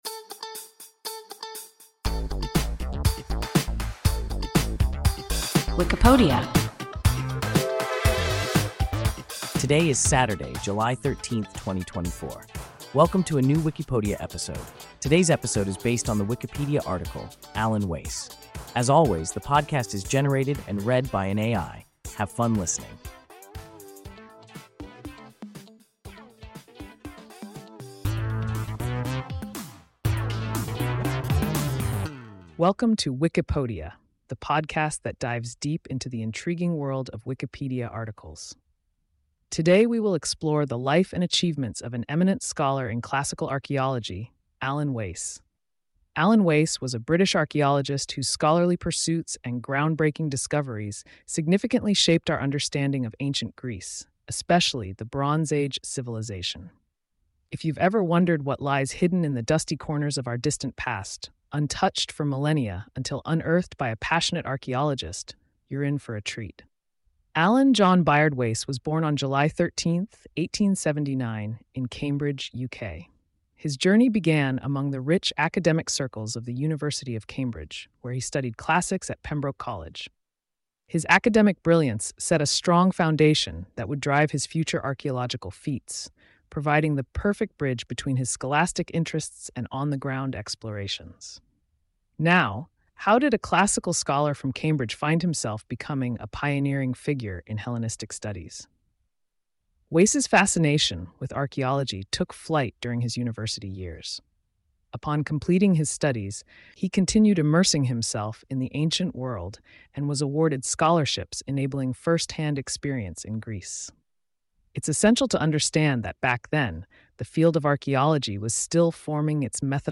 Alan Wace – WIKIPODIA – ein KI Podcast